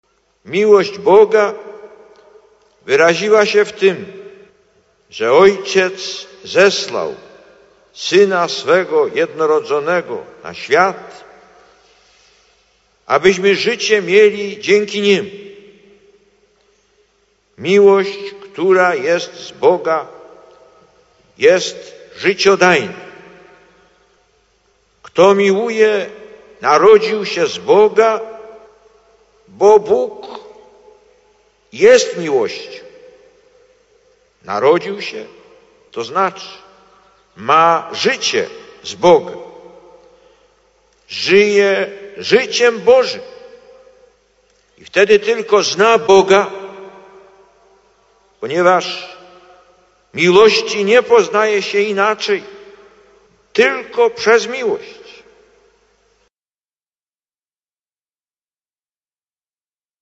Lektor: Z homilii podczas Mszy św. (Płock, 7 czerwca 1991 –